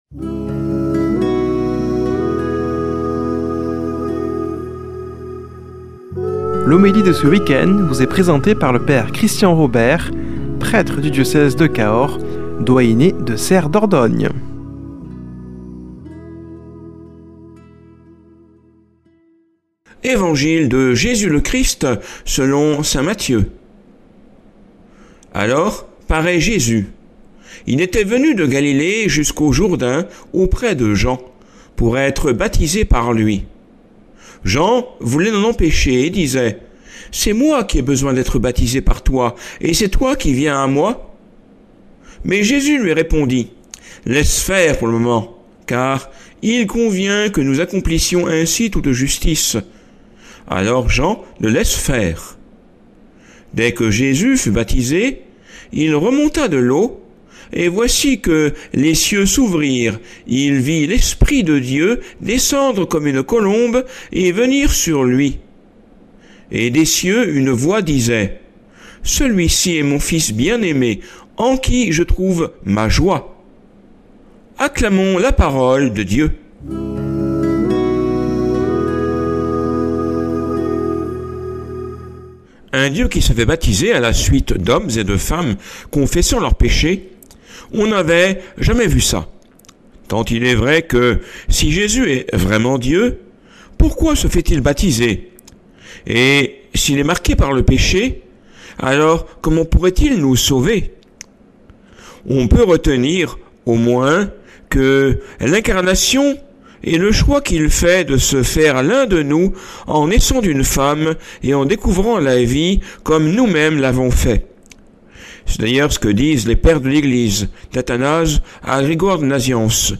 Homélie du 10 janv.